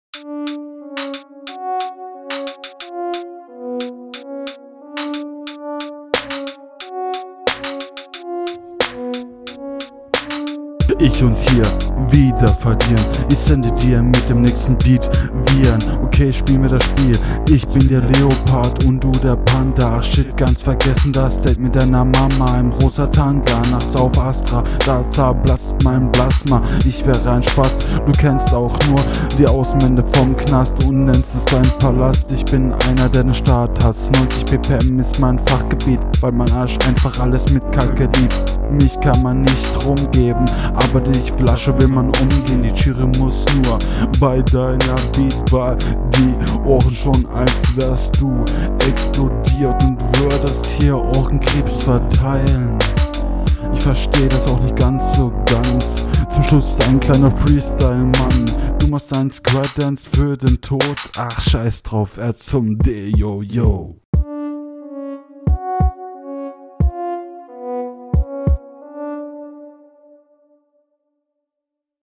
Du bist leider auch nicht auf dem takt, Flowlich seid ihr beide auf einer ebene, …